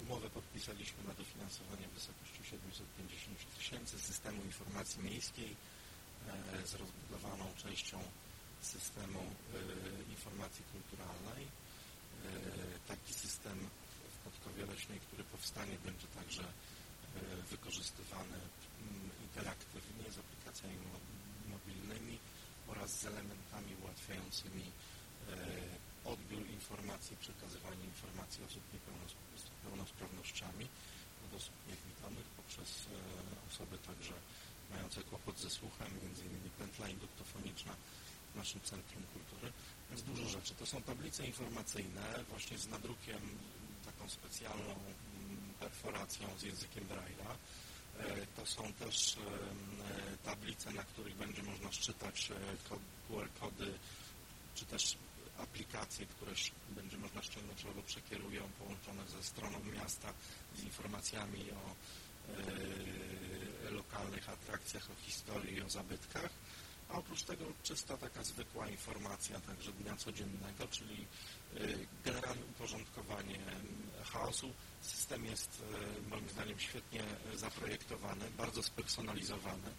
Wypowiedź burmistrza Podkowy Leśnej Artura Tusińskiego